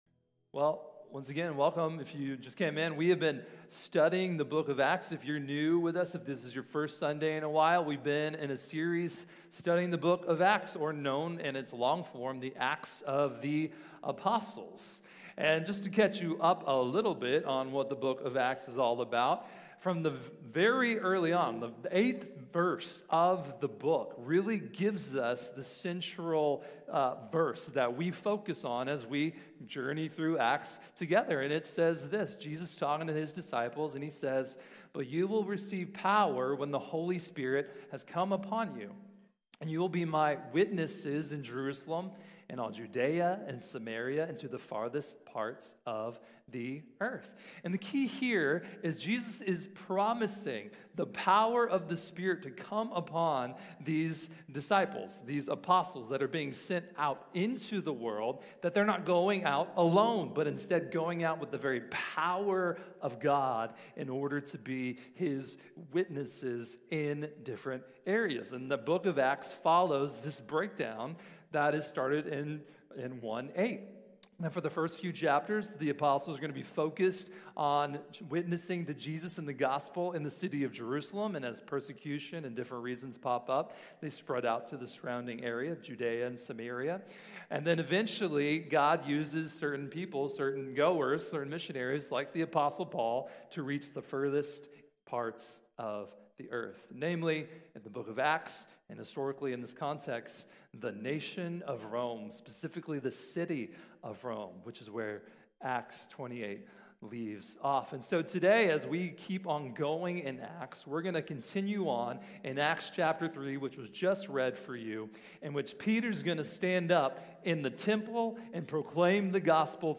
Healing for the Hurting | Sermon | Grace Bible Church